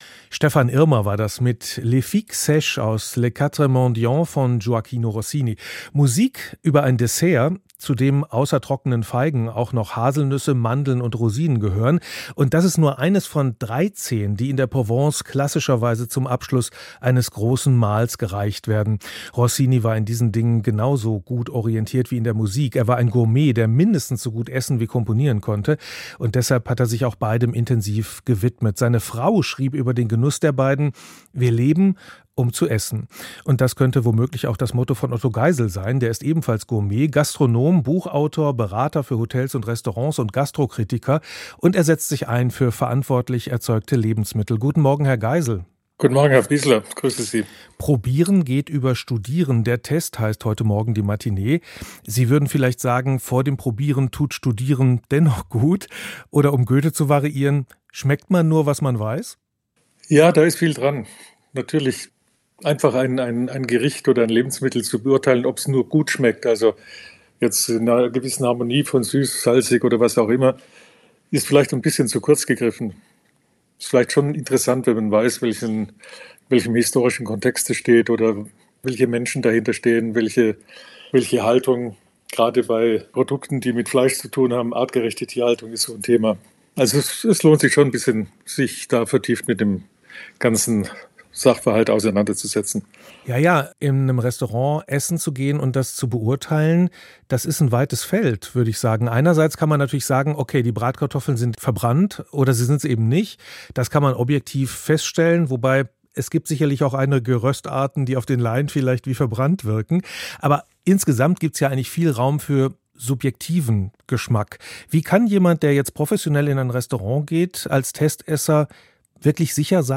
Interview
Ein Gespräch über die Kunst des Schmeckens und die subtilen Botschaften der Küche.